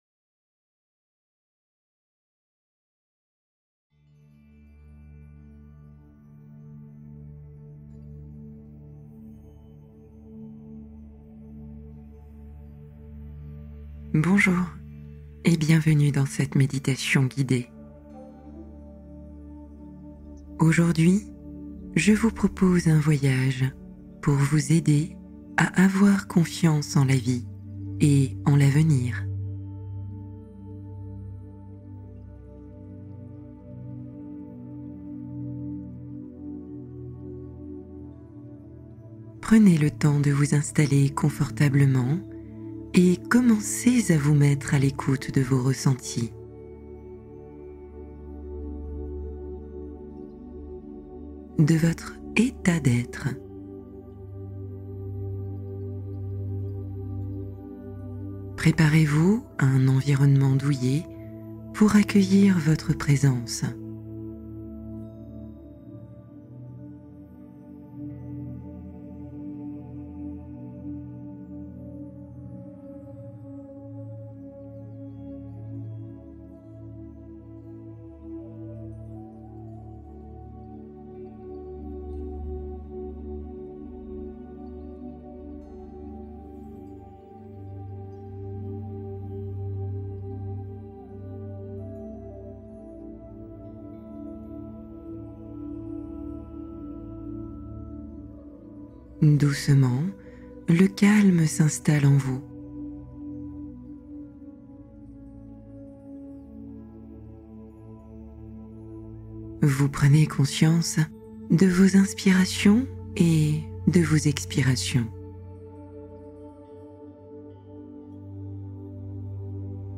Confiance en la vie : réouvrir l’avenir avec une relaxation apaisante